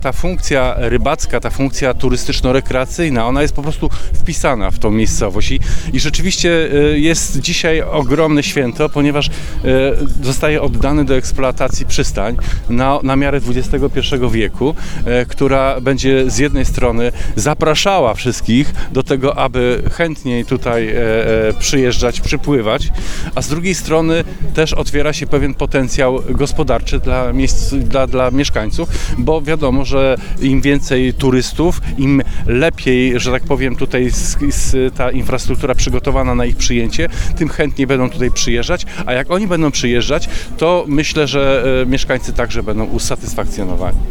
Rodzinny festyn zorganizowano w sobotnie popołudnie w Wolinie.
Im więcej turystów będzie chciało tu przyjeżdzać, tym mieszkańcy także będą usatysfakcjonowani – uważa Krzysztof Woś, prezes Wód Polskich.